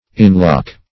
Inlock \In*lock"\, v. t. To lock in, or inclose.